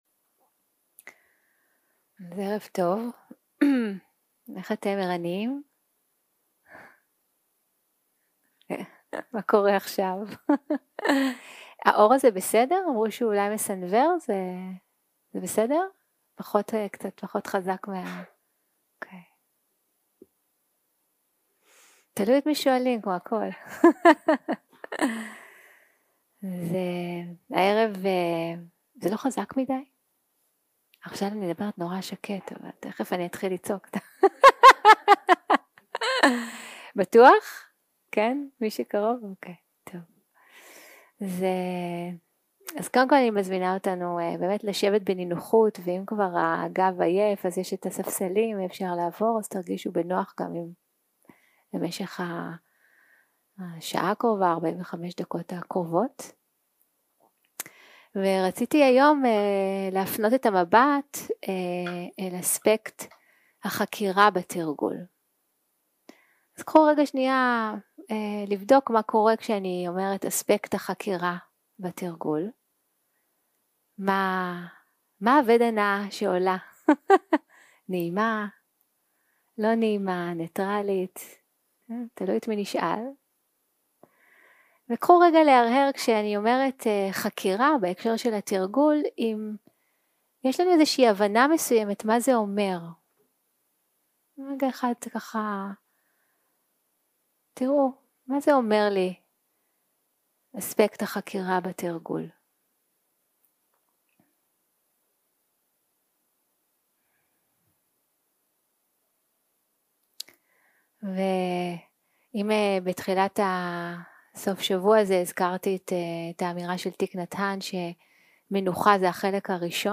יום 3 - הקלטה 6 - ערב - שיחת דהרמה - חקירה מדיטטיבית.
0:00 0:00 סוג ההקלטה: Dharma type: Inquiry שפת ההקלטה: Dharma talk language: Hebrew